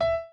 b_pianochord_v100l8o6e.ogg